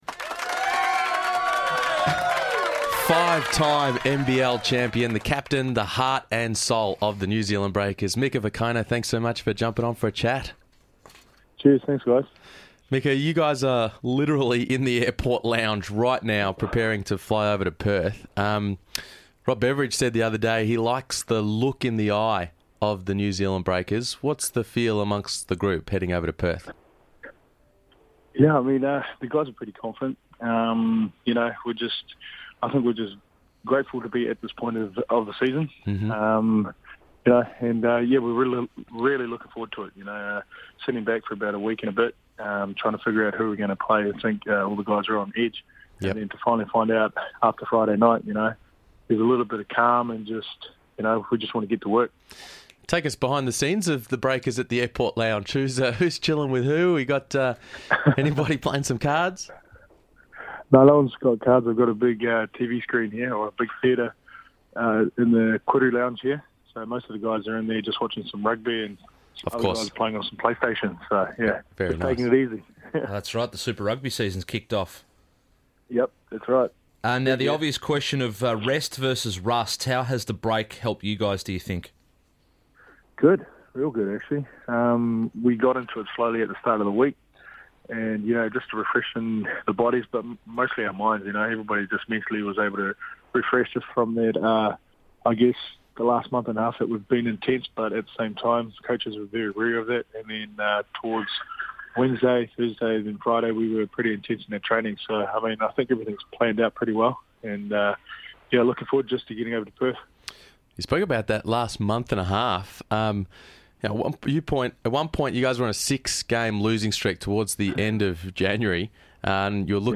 Mika Vukona Interview
Vukona joins the boys from the airport lounge ahead of flying to Perth to discuss New Zealand’s topsy-turvy season, his playoff mindset, whether Perth can match the Breakers’ intensity and his scout on Wildcats big man Matty Knight.